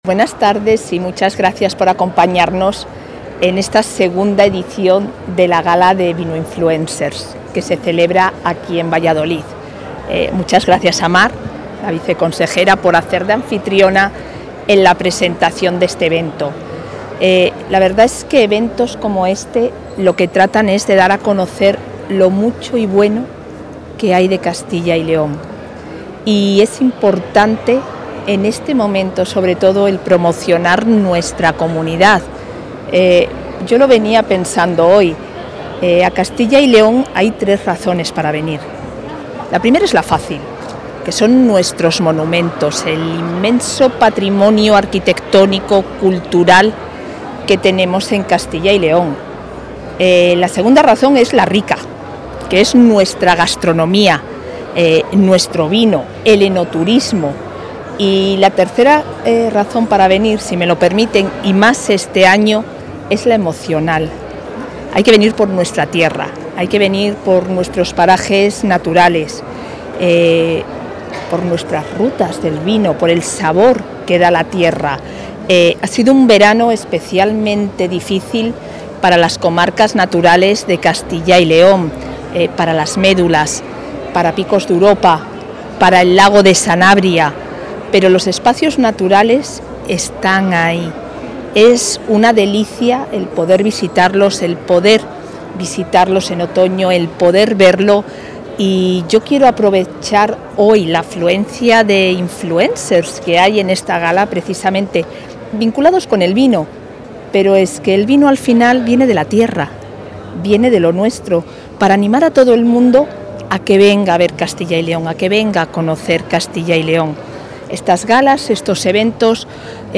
La vicepresidenta de la Junta de Castilla y León, Isabel Blanco, ha clausurado hoy la II Gala ‘Vinoinfluencers World Awards’, un...
Intervención de la vicepresidenta.
El Centro Cultural Miguel Delibes acoge la II Gala 'Vinoinfluencers World Awards', que ha reunido a más de cien comunicadores del mundo del vino procedentes de treinta países y que ha clausurado la número dos de la Junta.